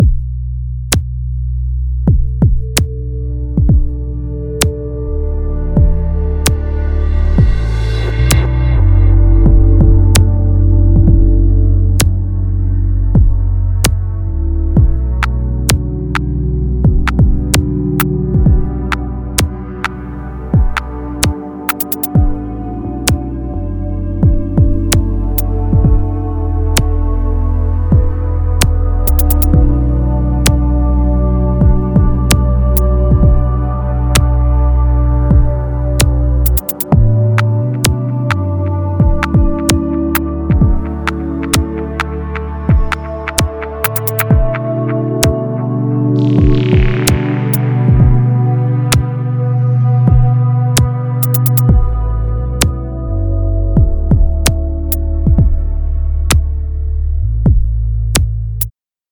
Ambient Loop